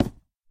wood3.ogg